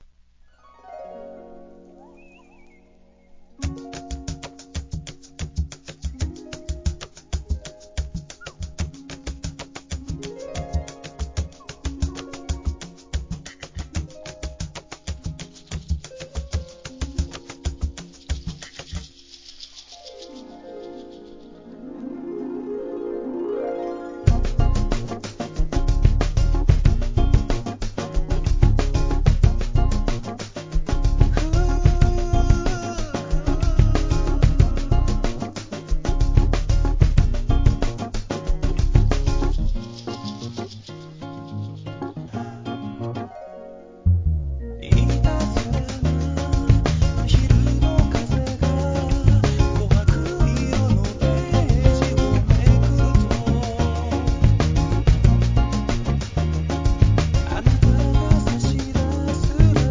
JAPANESE HIP HOP/R&B